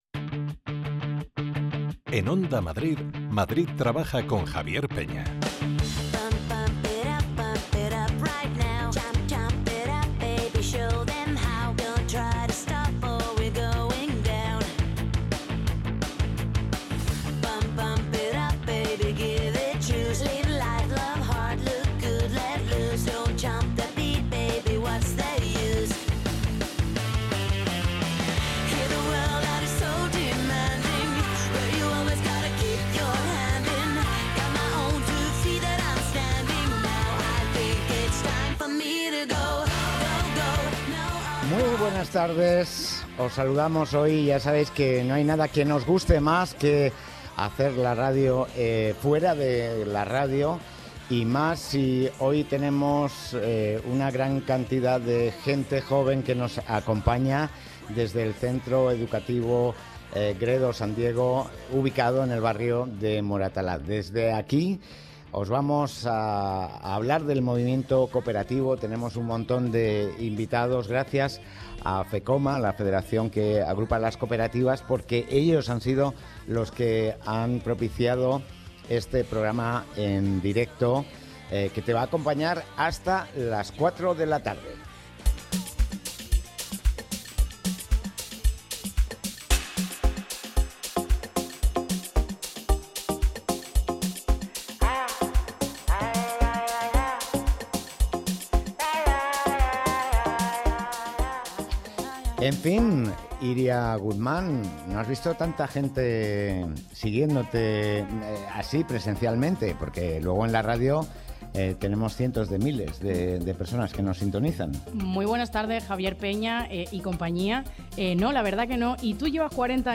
Madrid Trabaja 22.11.2024 emitido desde GSD Moratalaz gracias a Fecoma
Escucha el programa completo que hemos emitido hoy desde el Colegio GSD de Moratalaz en el que hemos hablado de economía social con Fecoma